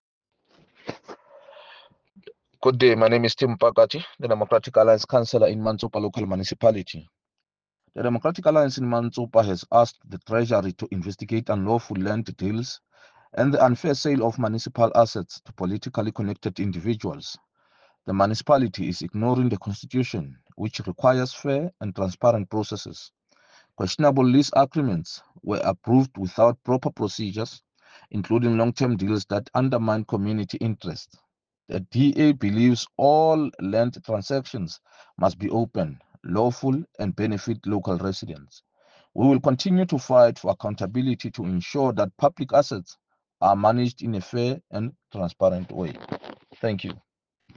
Sesotho soundbites by Cllr Tim Mpakathe and